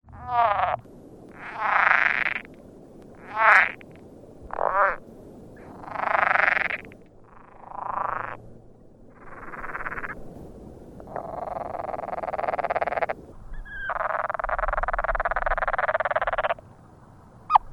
The main call can be described as a faint one-note low-pitched, raspy series of 4 - 6 notes per second. Grunts and oinks may also be heard, along with rattling.
Air temperature was 78 degrees F, water temperature 65 degrees F. Several male frogs were gathered in a small pool near some recently-deposited egg masses. Other frogs were situated in nearby pools and their calls could be heard faintly.
Sounds This is an 18 second composite of some of the various underwater sounds made by several frogs, taken from various recordings.
ranaboyliicomposite.mp3